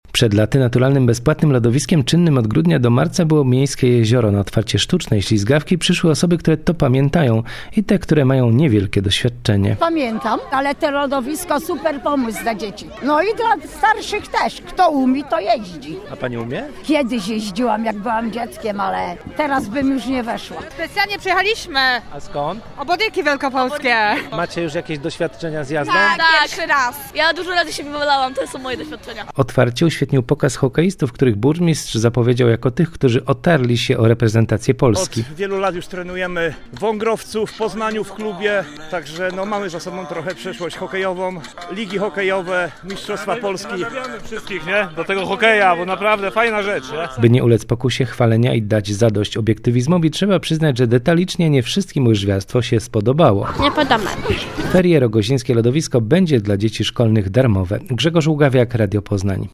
Na miejscu był nasz dziennikarz.